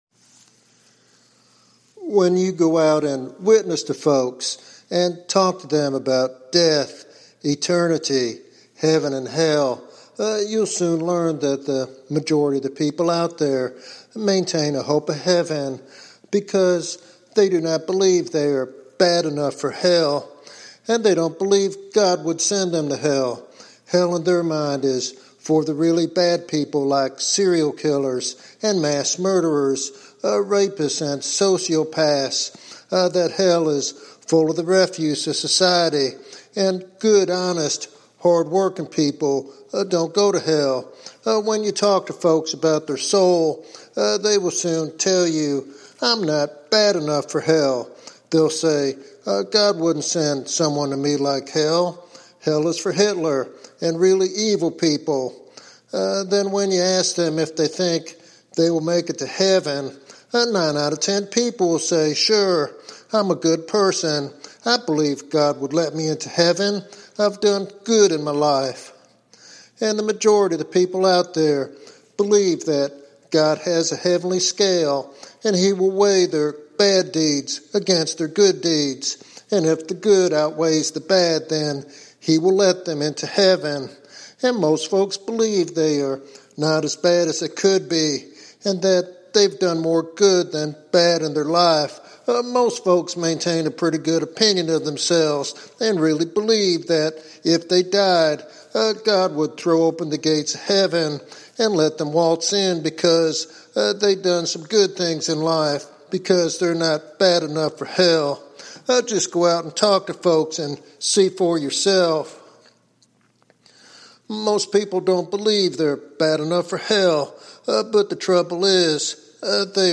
In this evangelistic sermon